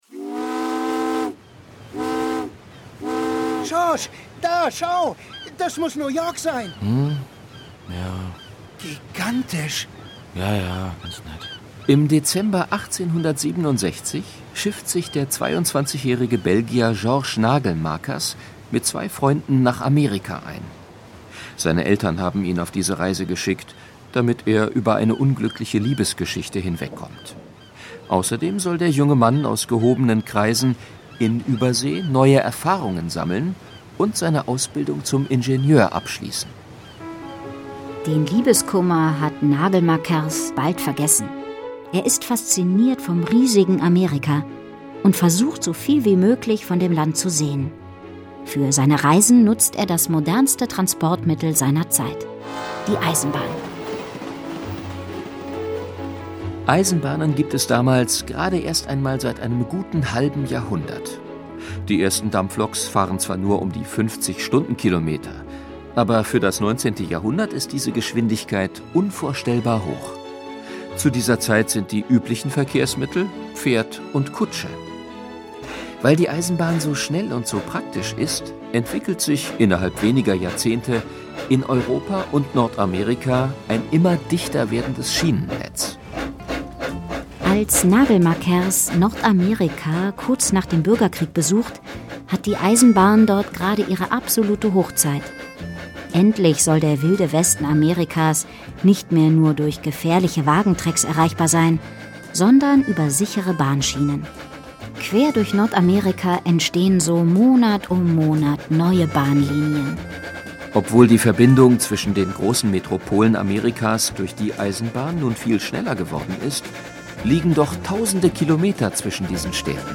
Schlagworte Asien • Erde • Hörbuch für Kinder/Jugendliche • Hörbuch für Kinder/Jugendliche (Audio-CD) • Hörbuch; Hörspiel für Kinder/Jugendliche • Orient • Orient-Express • Orient-Express; Kindersachbuch/Jugendsachbuch • Orient-Express; Kindersachbuch/Jugendsachbuch (Audio-CDs) • Rätsel • Reise • Zug